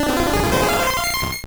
Cri de Parasect dans Pokémon Or et Argent.